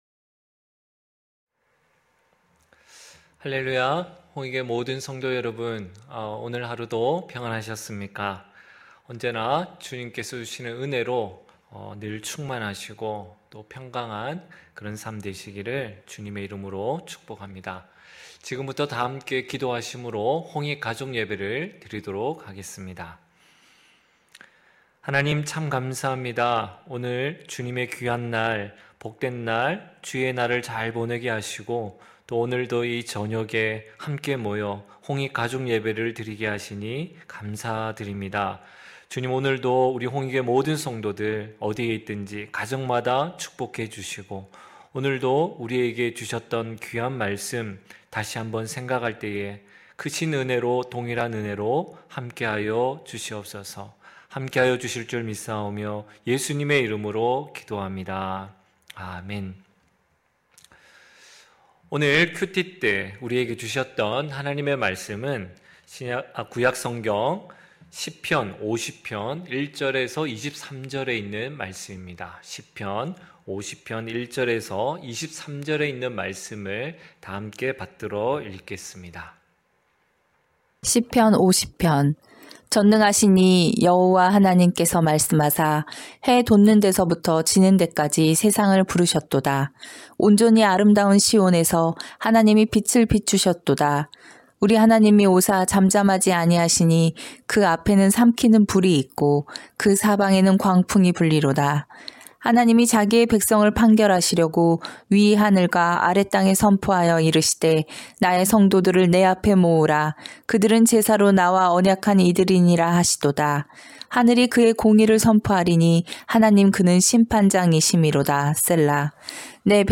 9시홍익가족예배(2월28일).mp3